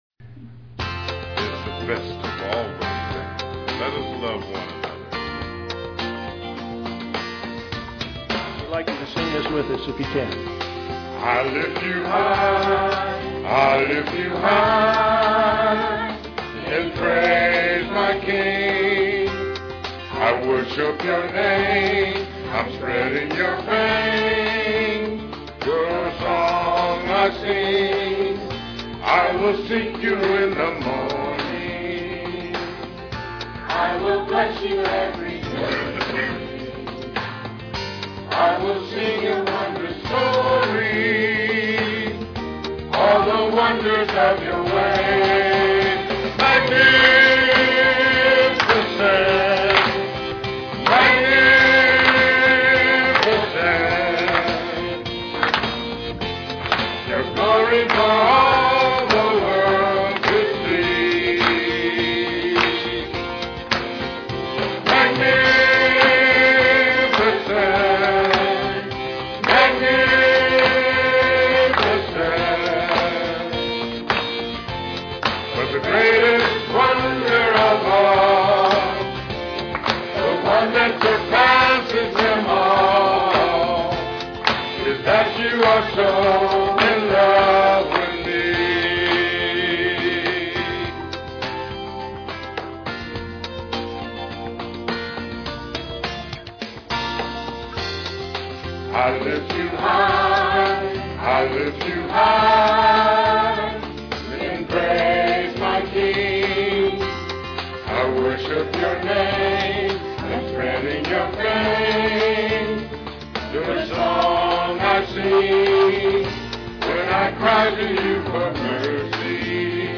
Sung by ABC Choir.